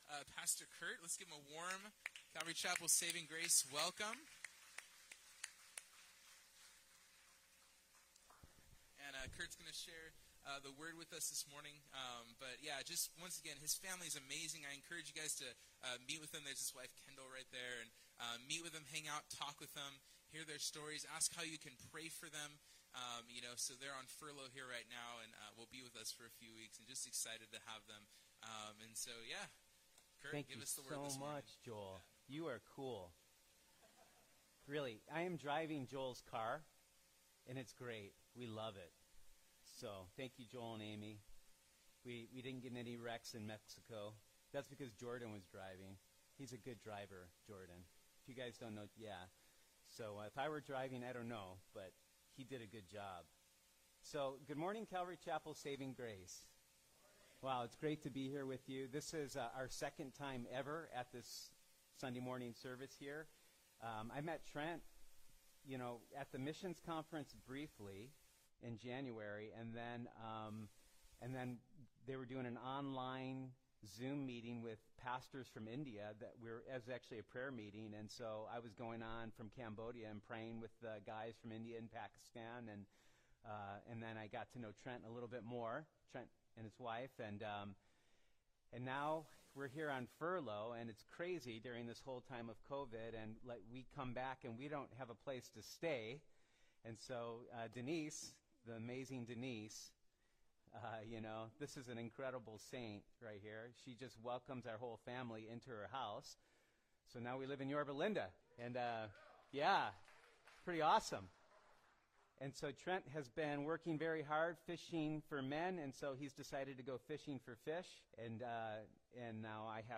Guest Speakers « 1 Thessalonians 5:8-11